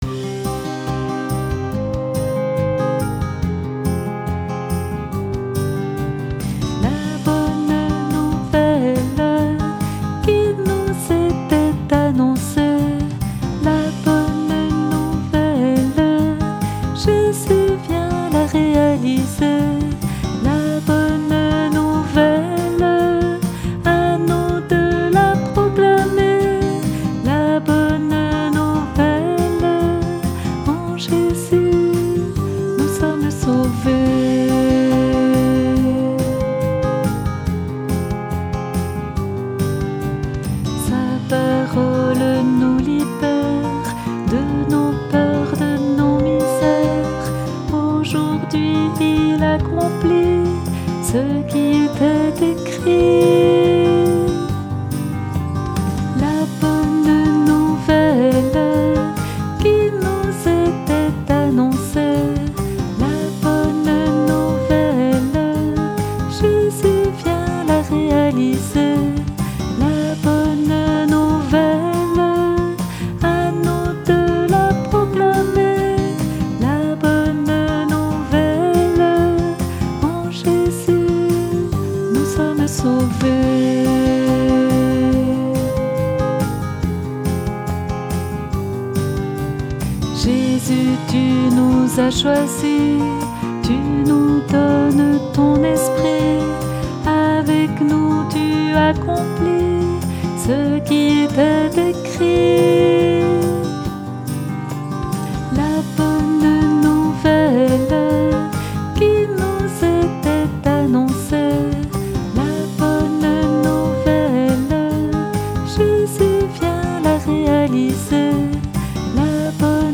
Chant « La Bonne Nouvelle »